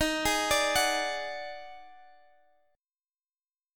D#mM7bb5 chord